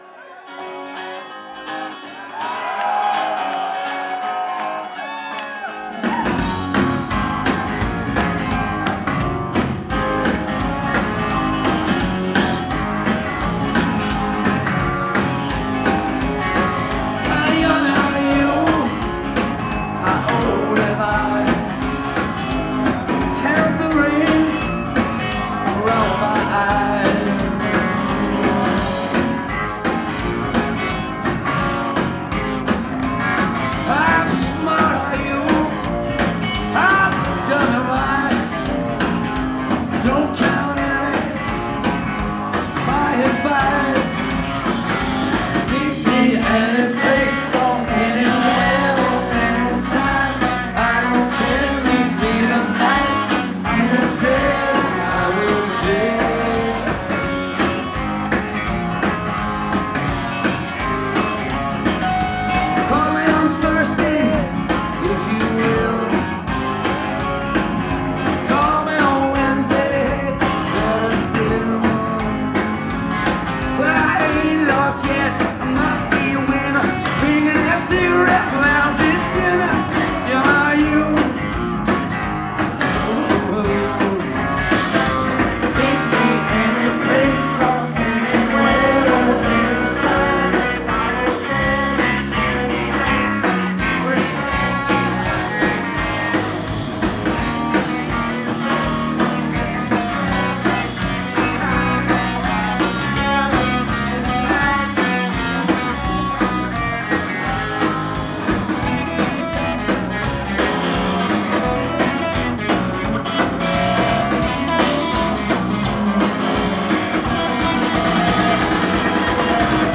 Live at the Sinclair, Cambridge, MA